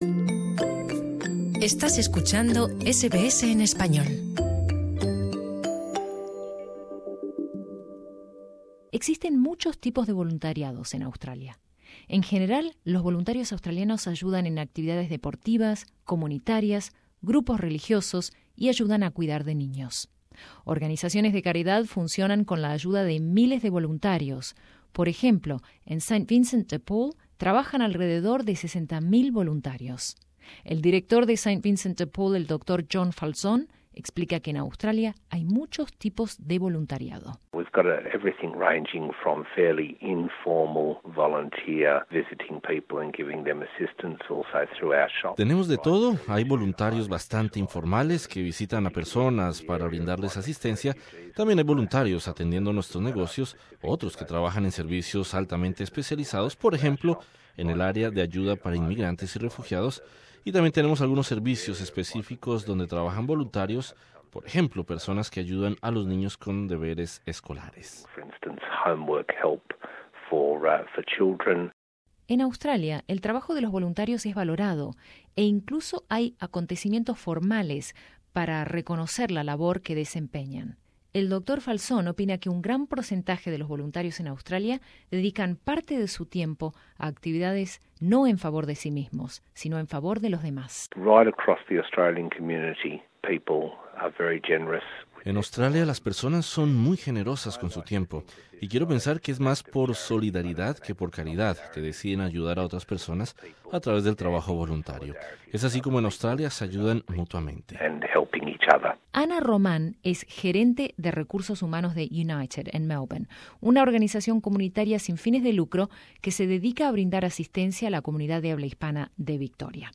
Las ventajas de hacer un trabajo voluntario en Australia son reales. Escucha nuestro informe: